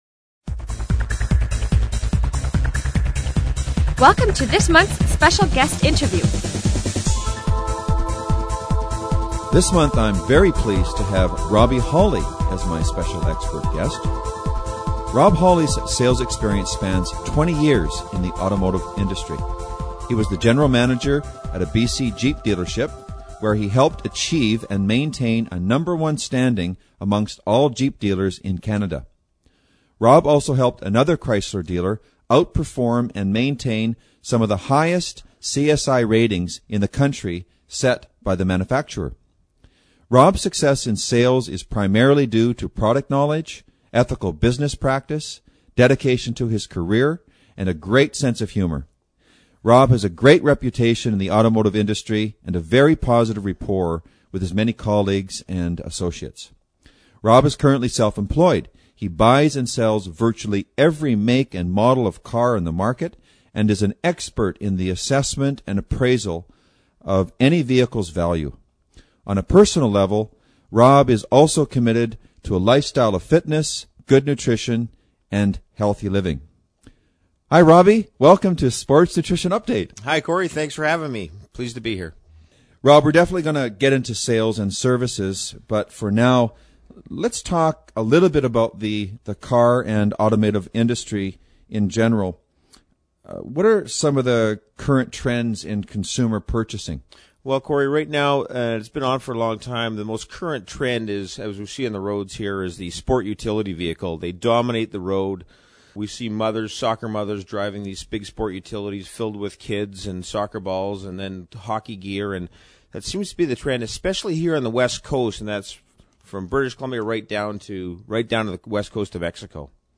Special Guest Interview Volume 2 Number 3 V2N3c